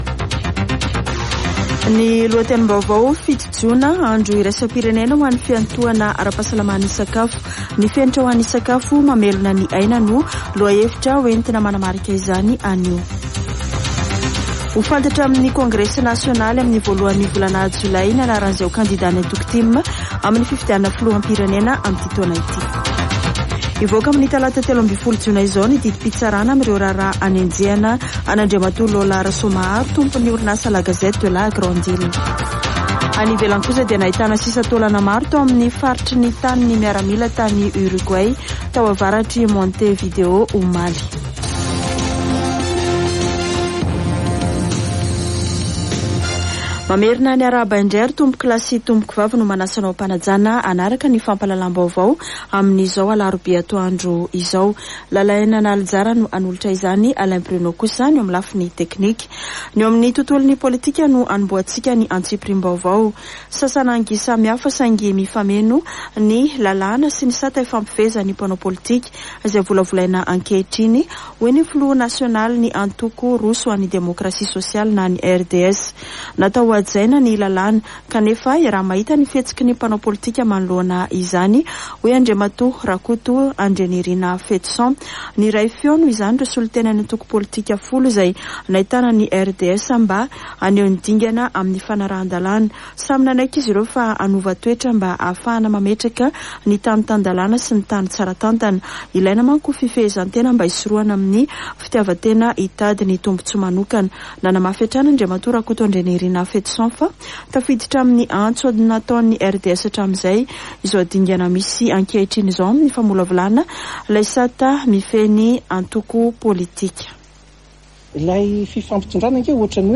[Vaovao antoandro] Alarobia 7 jona 2023